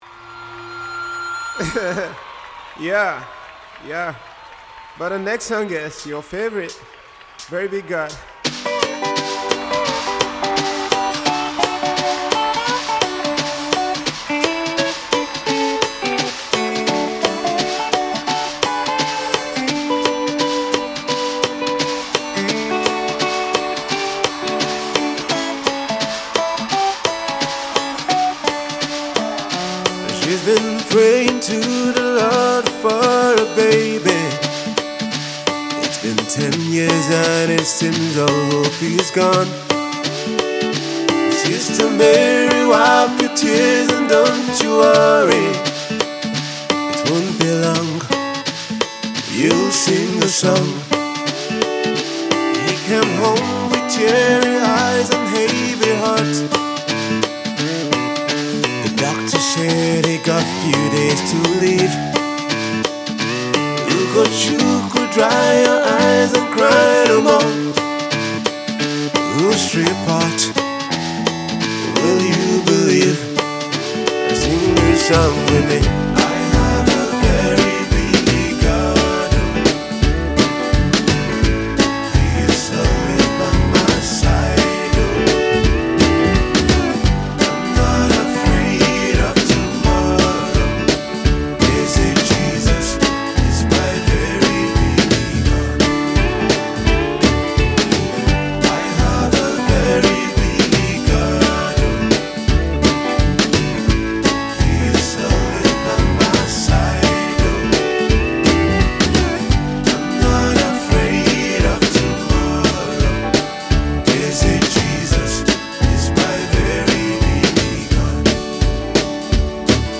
country music themed song